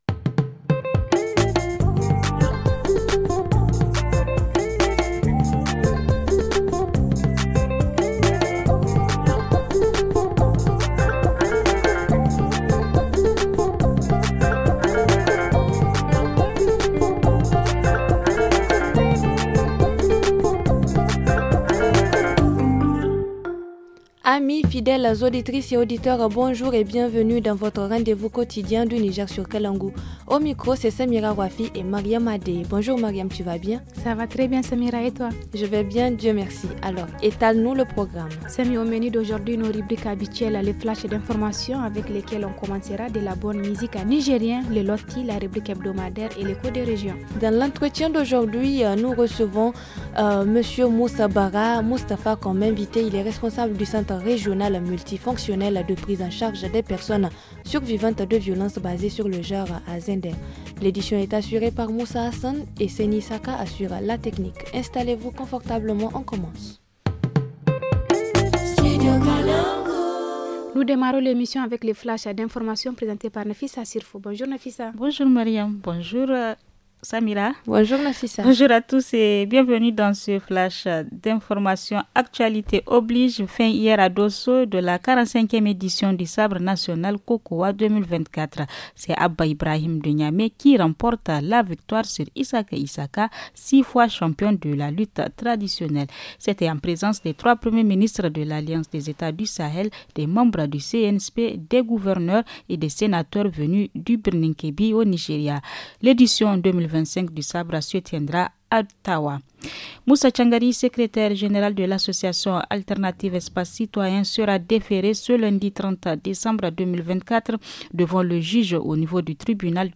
Dans la rubrique hebdo, protection des droits de la femme et des enfants contre les violences basées sur les genres à N’guigmi. En reportage région, retour sur la semaine dédiée à la volaille du 24 au 30 décembre.